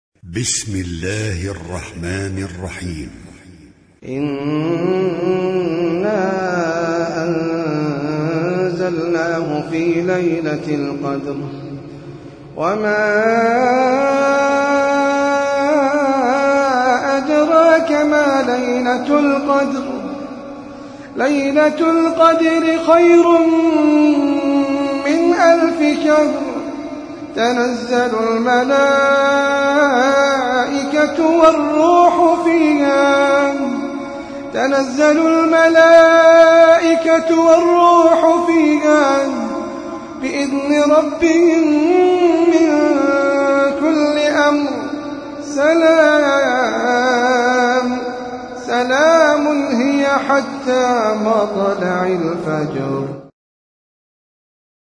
سورة القدر - المصحف المرتل (برواية حفص عن عاصم
جودة عالية